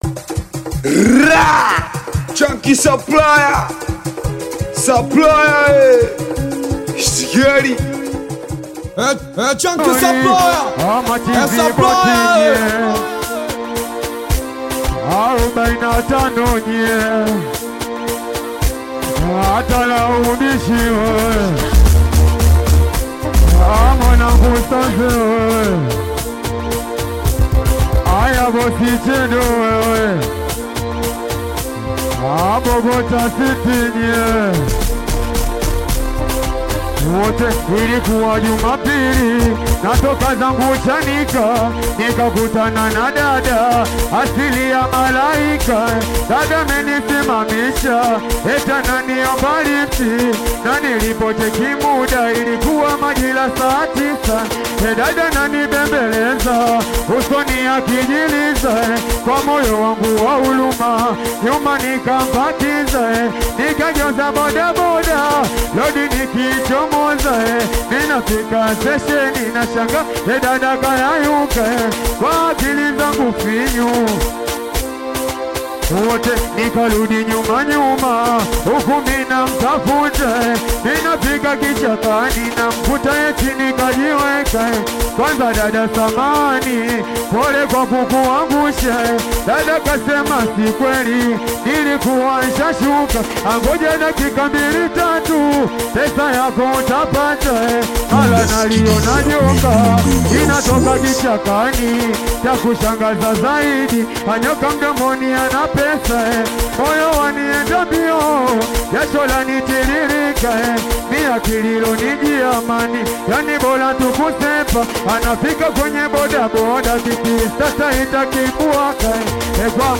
SHOW LIVE SINGELI